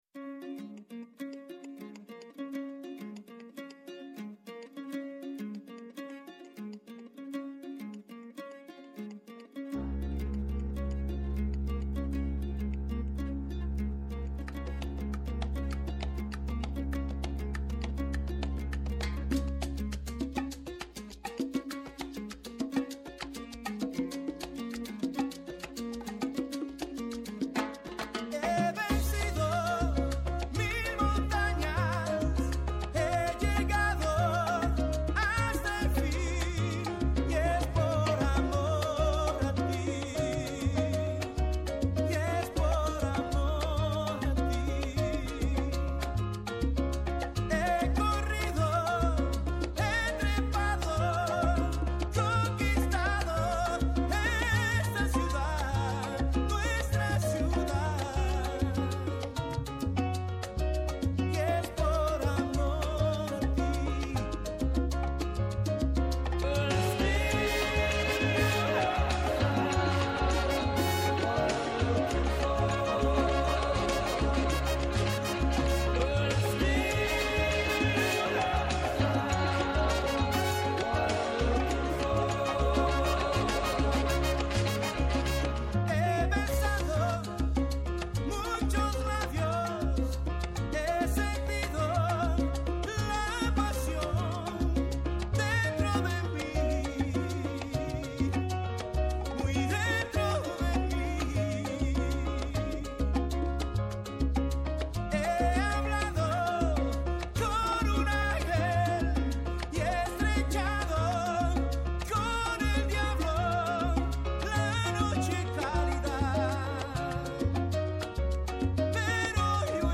-Ο Αλέξανδρος Κόπτσης, Γενικός Γραμματέας Παιδείας